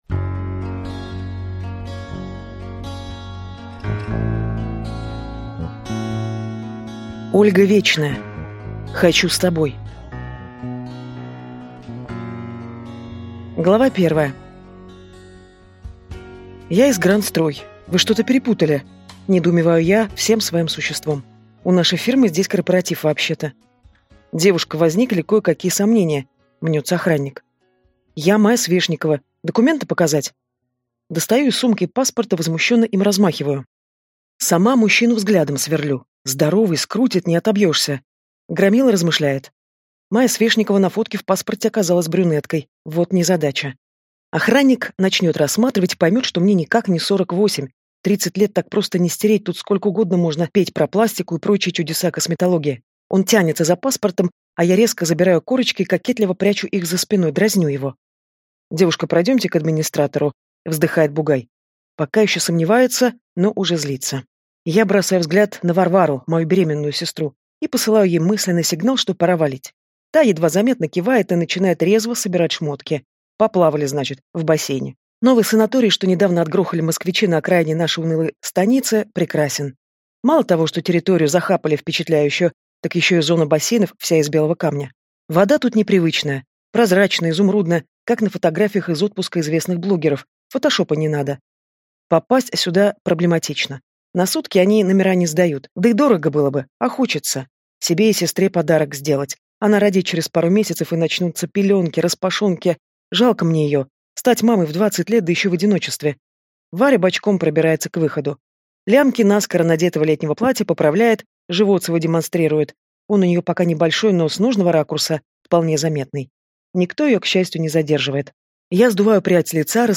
Аудиокнига Хочу с тобой | Библиотека аудиокниг
Прослушать и бесплатно скачать фрагмент аудиокниги